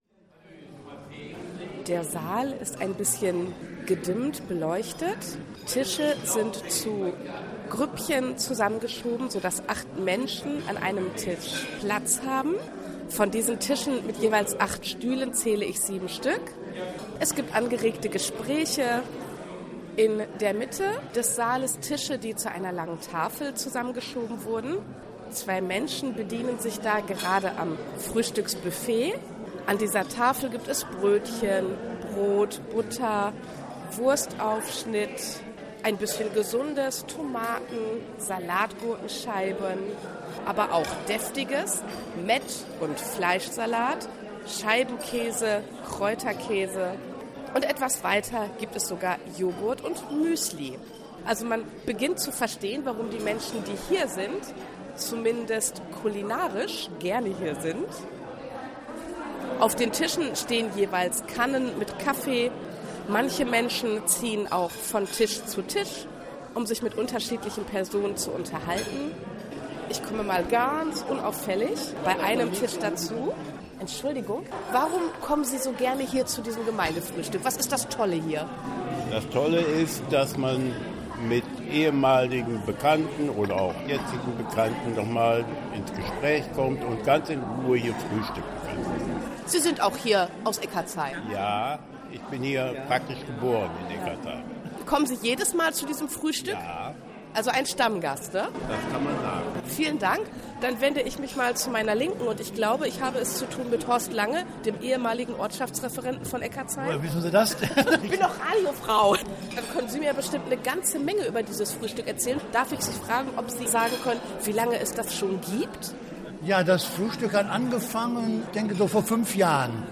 Frühstück im Thekoa-Saal in Eckardtsheim
Reportage-Gemeindefruehstueck-Eckardtsheim.mp3